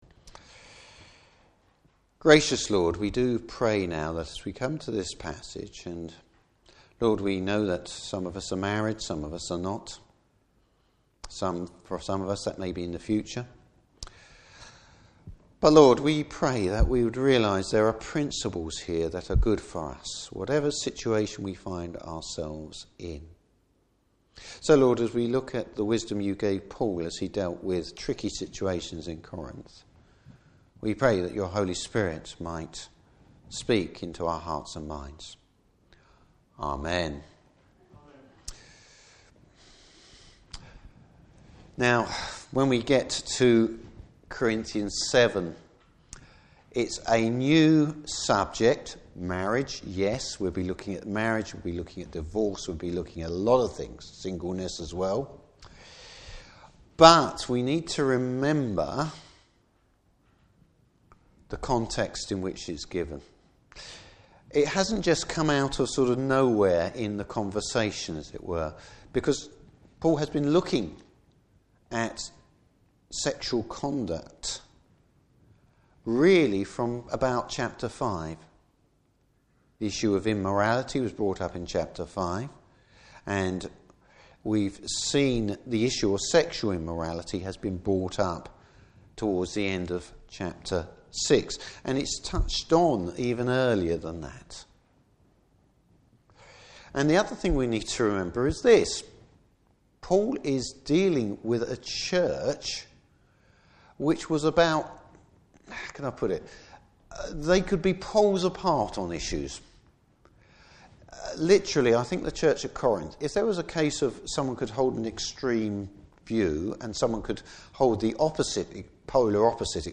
Passage: 1 Corinthians 7:1-9. Service Type: Morning Service Sexaul conduct in the Church.